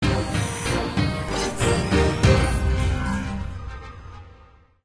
brawl_robotfactory_load_01.ogg